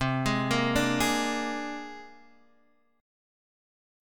C7sus2#5 Chord